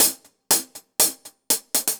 Index of /musicradar/ultimate-hihat-samples/120bpm
UHH_AcoustiHatC_120-03.wav